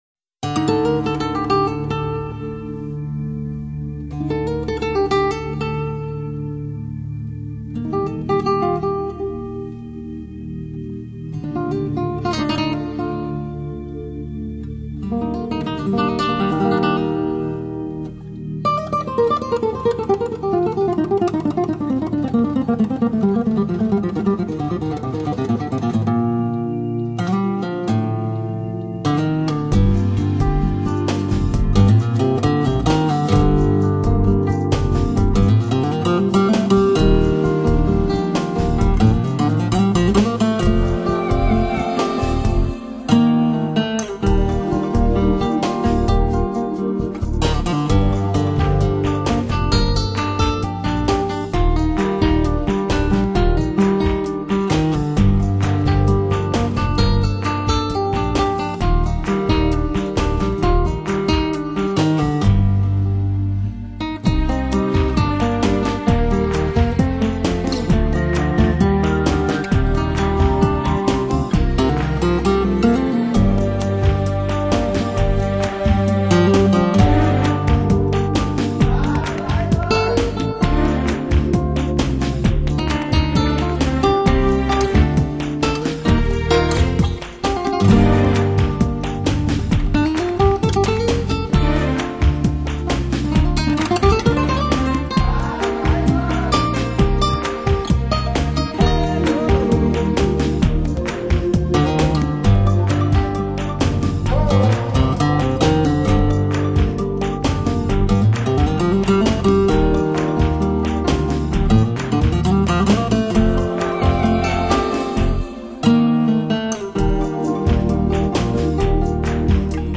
缓慢沉重的节奏疯狂迷幻的Punk吉他 还有一点电子噪音